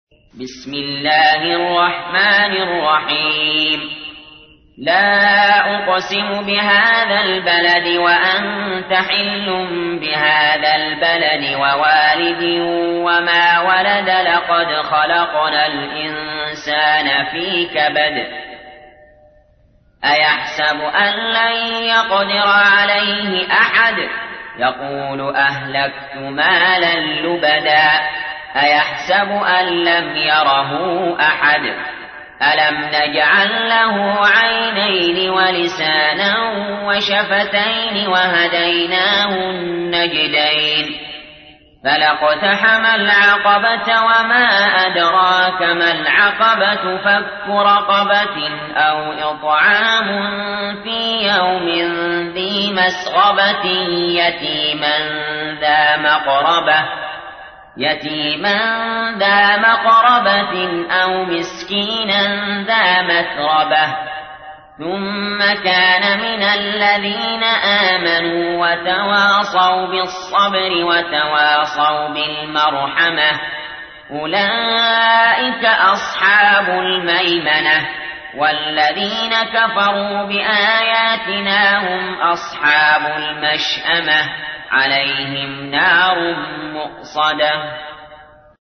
تحميل : 90. سورة البلد / القارئ علي جابر / القرآن الكريم / موقع يا حسين